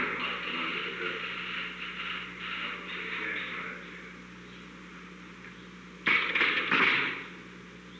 Secret White House Tapes
Conversation No. 902-11
Location: Oval Office
Unknown men met.
Furniture